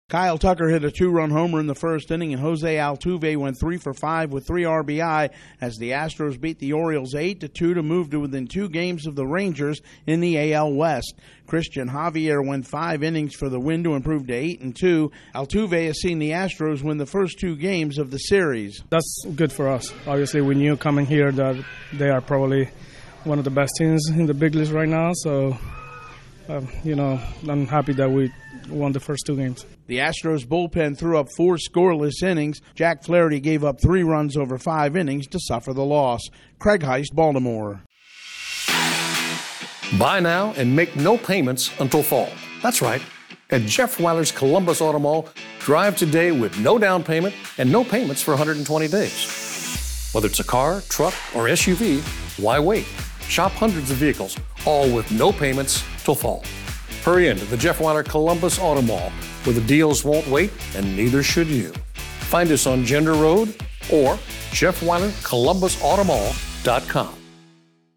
The Astros rough up the American League's top team. Correspondent